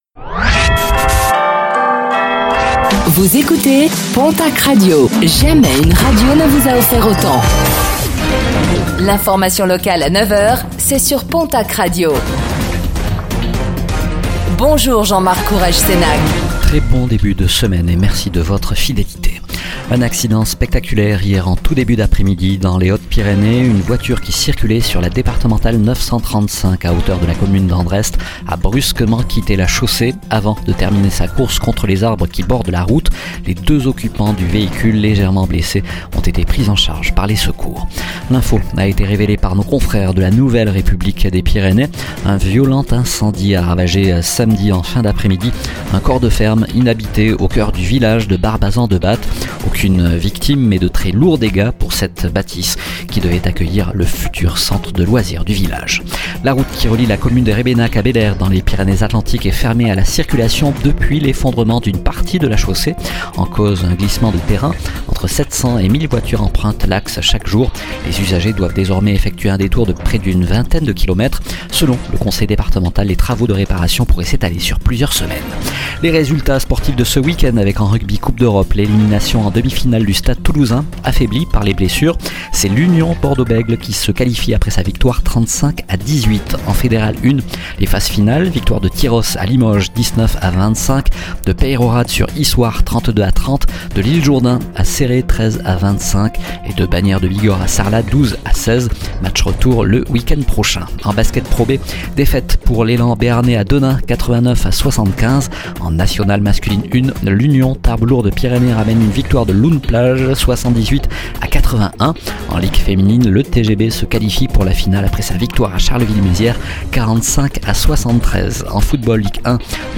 Infos | Lundi 05 mai 2025